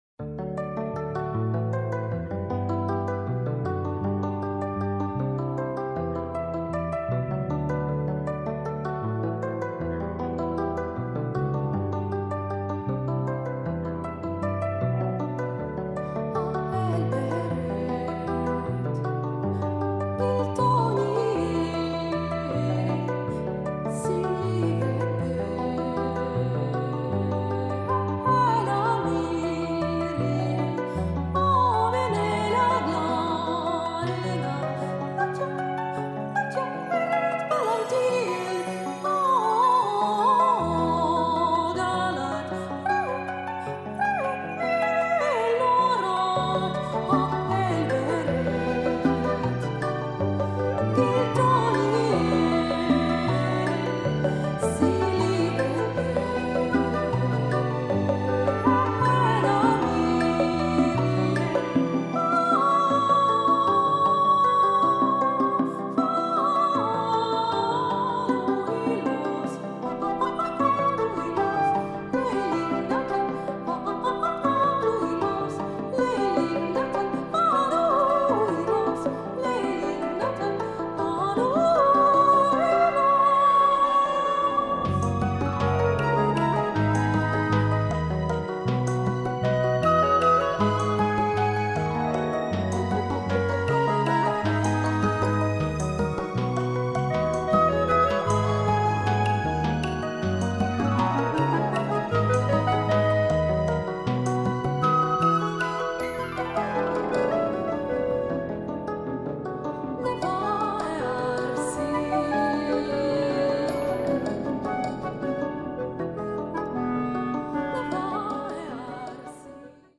プログレです！